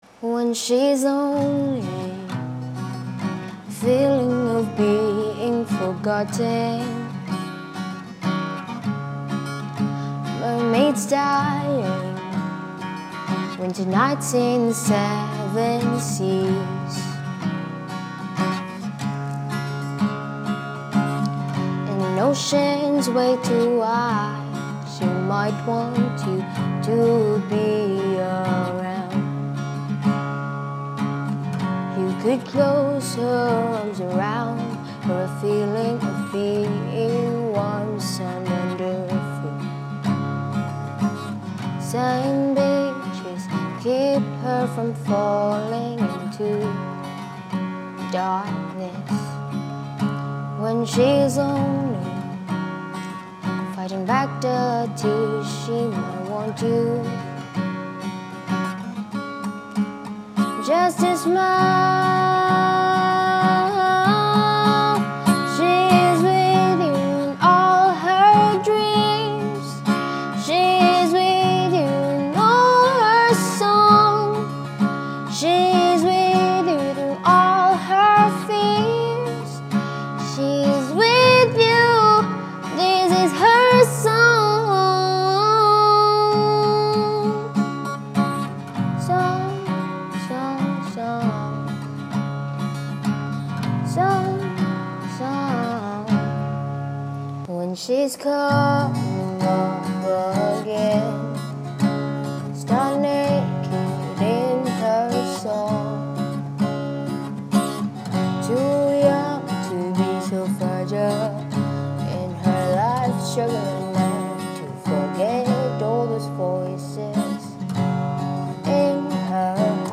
Recorded in Roxas City, Rock City